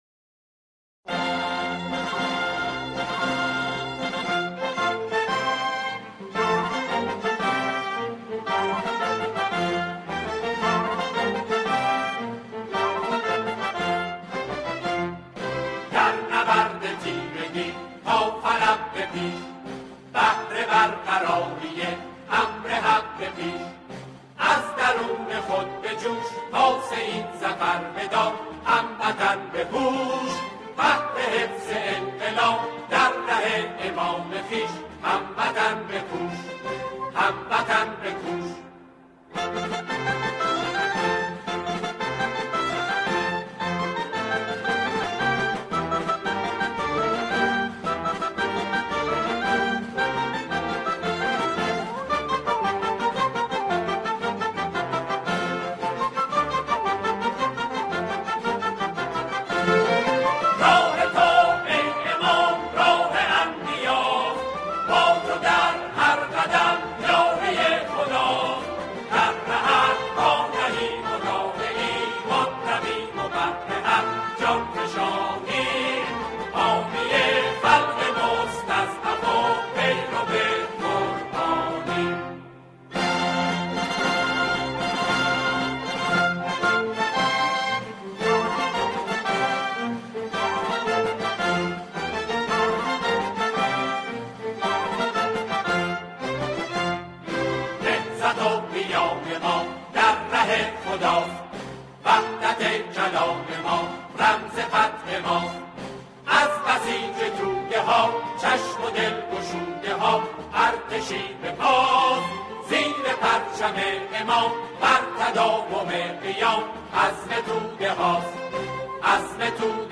سرود نوستالژی